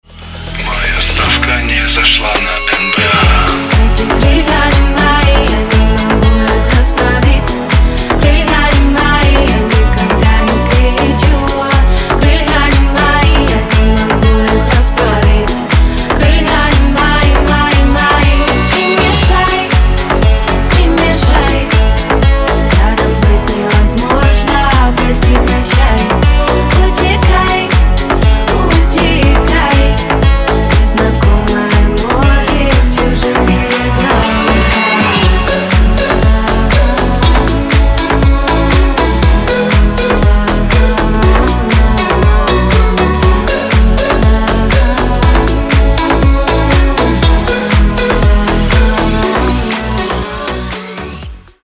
deep house
Club House
красивый женский голос